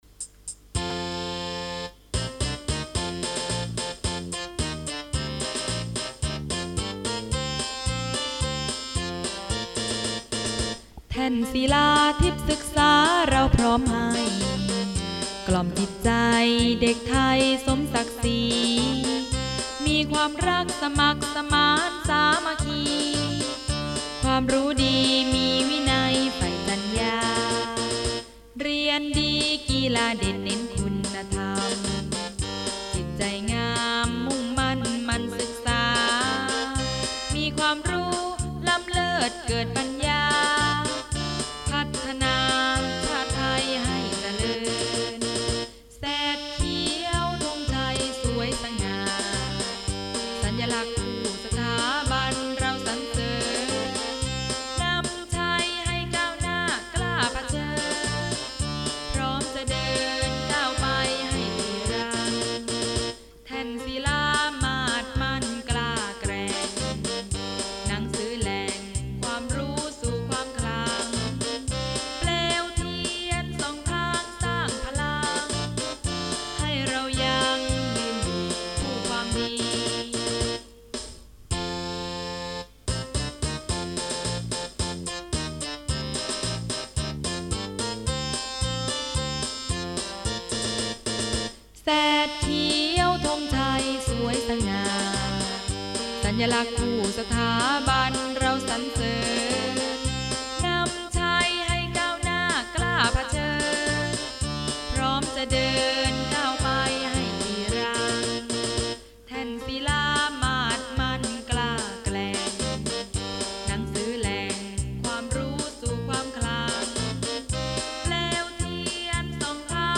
เพลงมาร์ชโรงเรียน